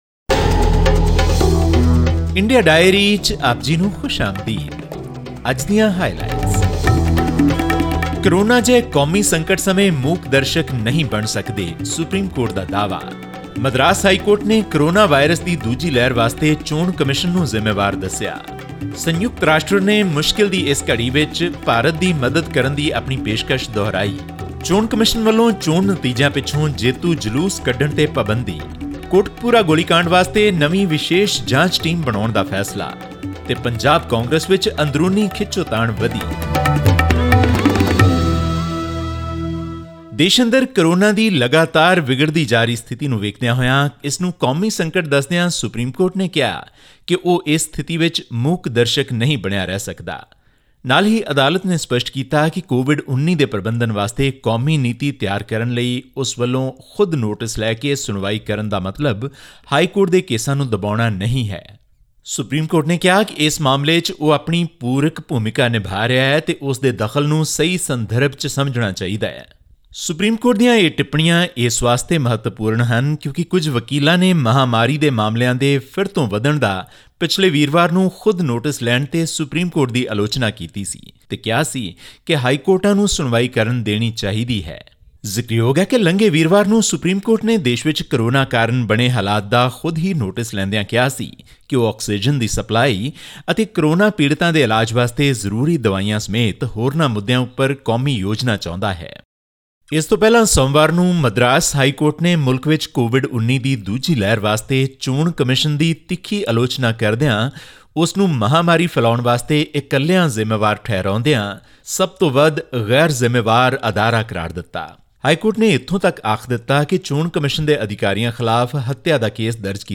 India's apex court has said that it cannot be a mute spectator to a national crisis as it asked the government to clarify the 'basis and rationale' on which COVID-19 vaccines are being priced in the country. This and more in our weekly news bulletin from India.